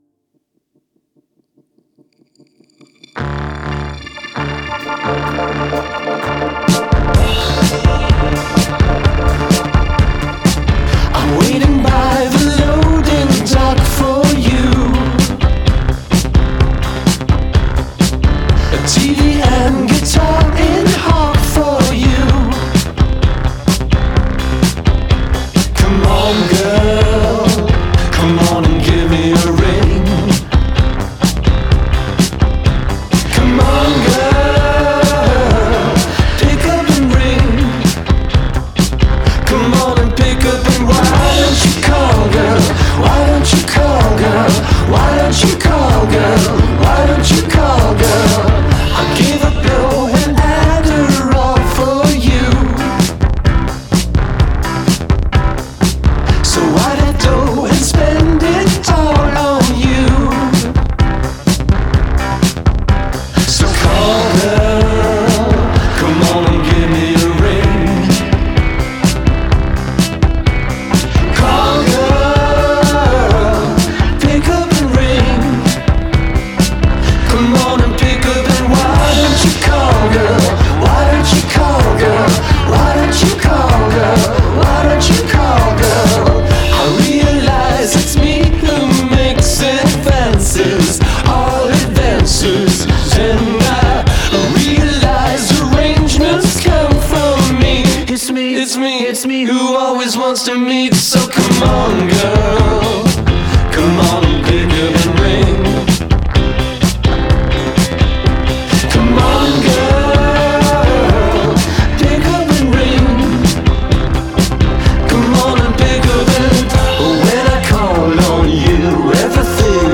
Genre: Indie Rock